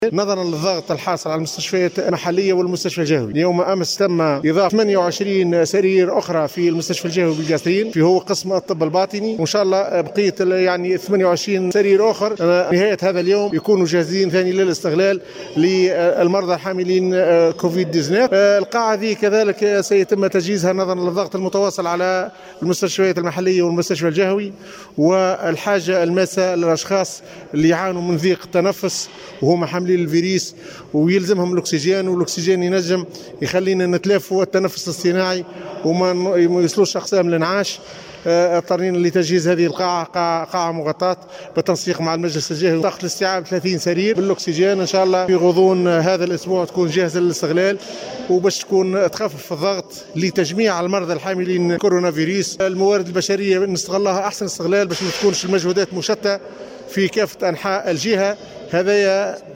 اكثر تفاصيل في التسجيل التالي للمدير الحهوي للصحة الدكتور عبد الغني الشعباني